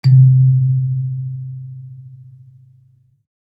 kalimba_bass-C2-mf.wav